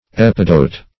Epidote - definition of Epidote - synonyms, pronunciation, spelling from Free Dictionary
Epidote \Ep"i*dote\, n. [Gr.